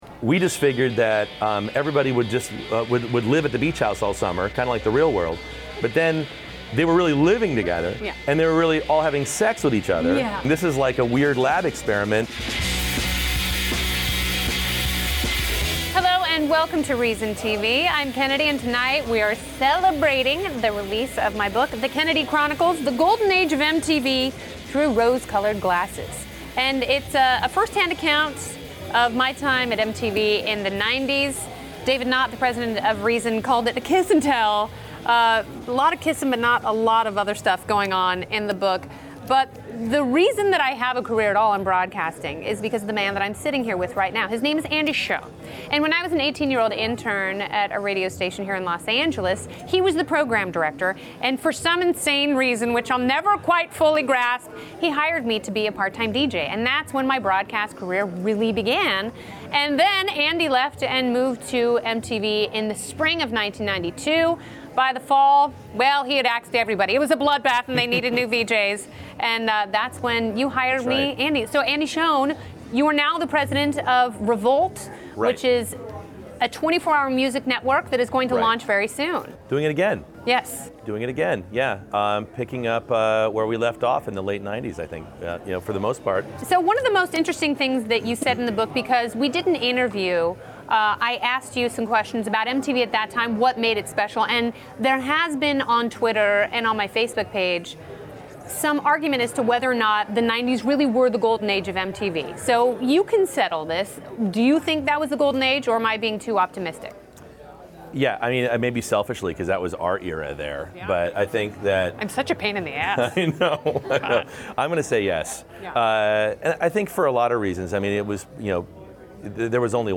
Last Thursday night, a crowd gathered at Reason's LA headquarters to celebrate the release of Kennedy's new book, The Kennedy Chronicles!